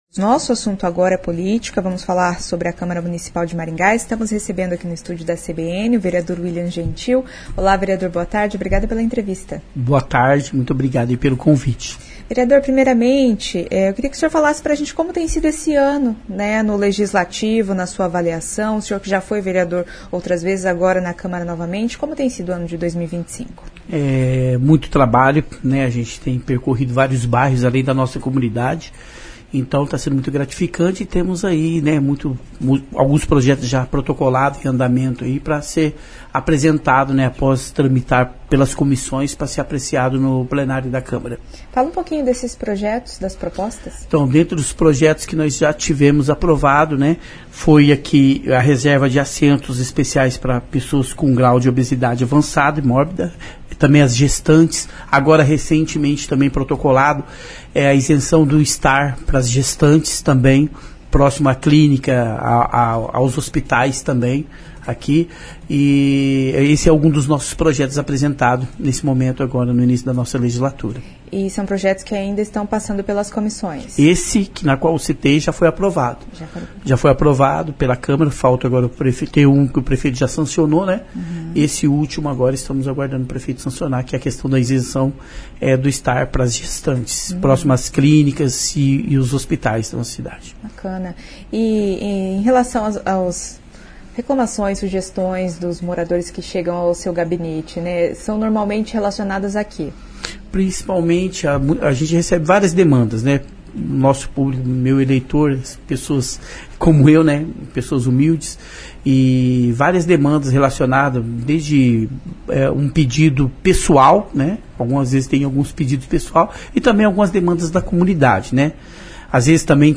Em entrevista à CBN Maringá, o vereador Willian Gentil falou sobre os projetos e o trabalho nesta legislatura. Entre as propostas aprovadas, uma lei que garante vaga de estacionamento para gestantes perto de clínicas e hospitais. Segundo ele, as principais cobranças da população são relacionadas à saúde e segurança.